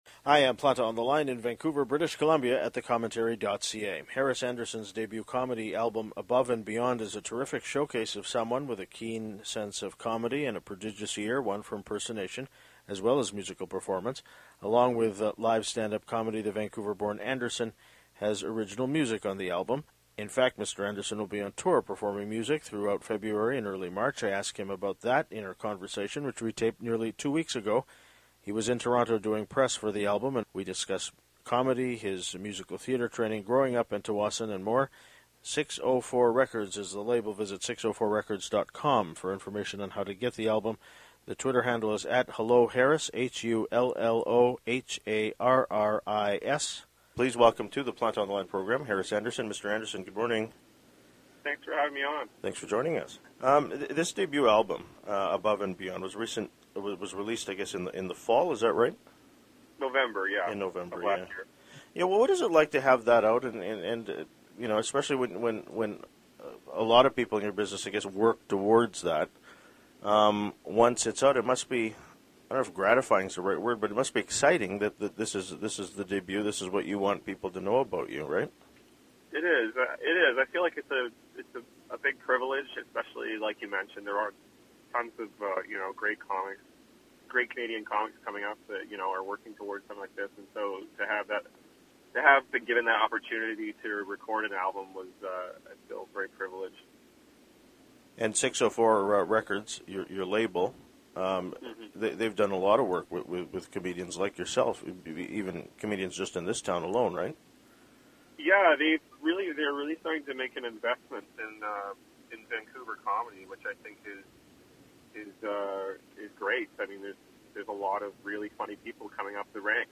I ask him about that in our conversation which we taped nearly two weeks ago.